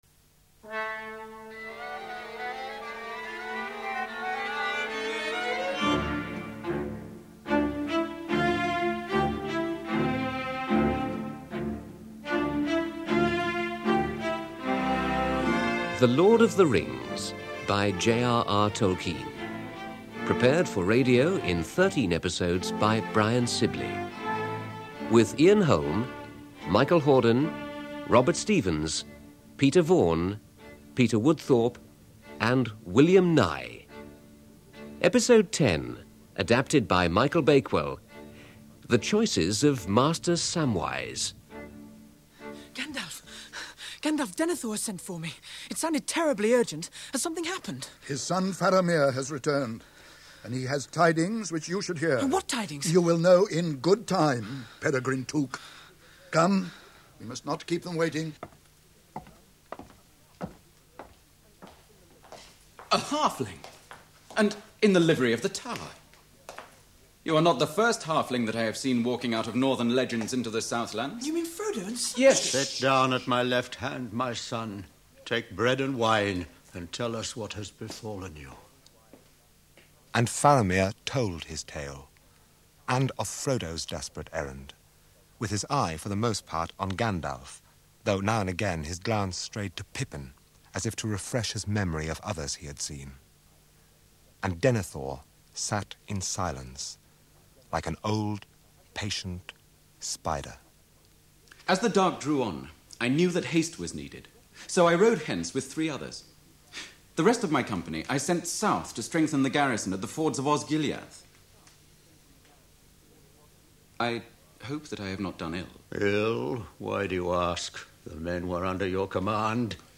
Thirteen hour mega dramatisation of "The Lord of the Rings" done by the Beeb in the eighties